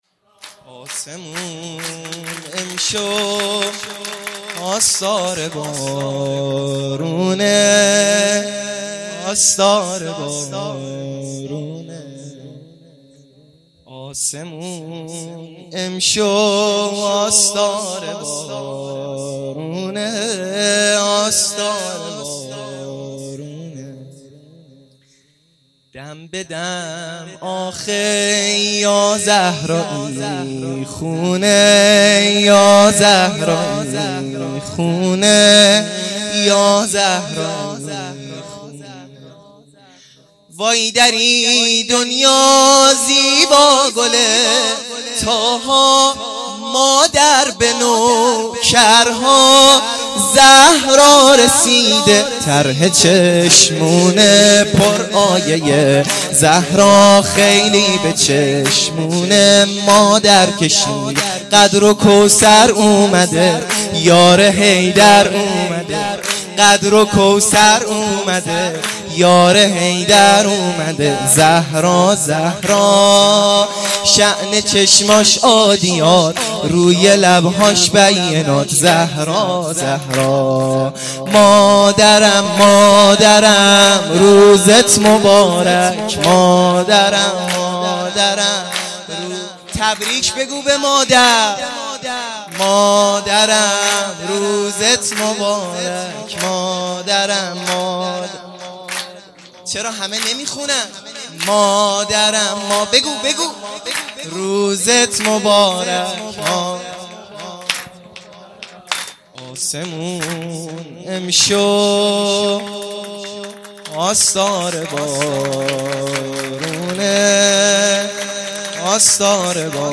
سرود
عیدانه حضرت زهرا سلام الله علیها